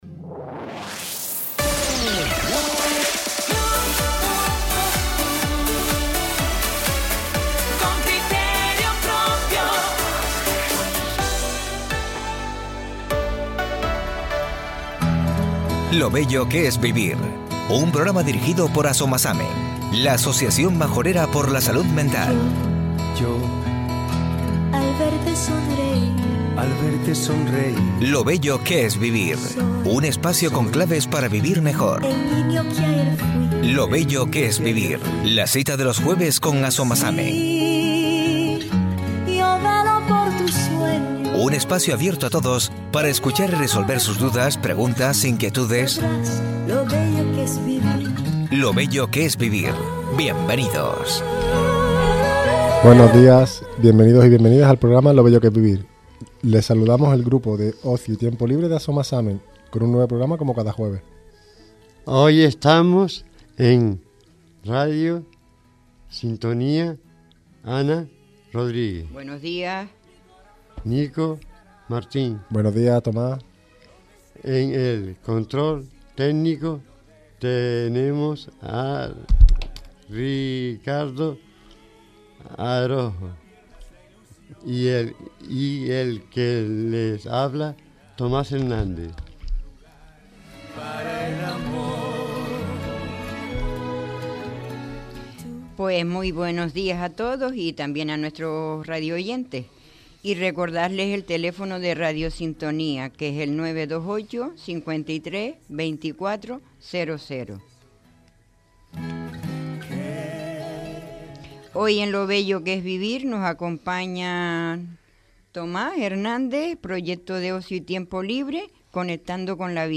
En Lo Bello que es Vivir nos acompañan los chicos y chicas del proyecto de Ocio y Tiempo Libre "Conectando con la Vida"